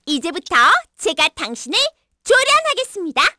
Hanus-vox-get_kr_c.wav